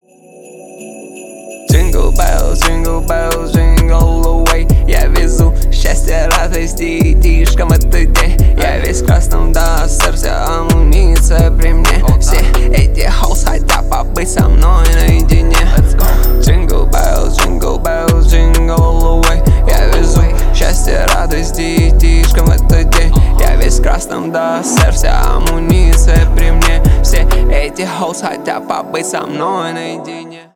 рэп , хип-хоп